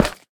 Minecraft Version Minecraft Version 25w18a Latest Release | Latest Snapshot 25w18a / assets / minecraft / sounds / block / froglight / step6.ogg Compare With Compare With Latest Release | Latest Snapshot
step6.ogg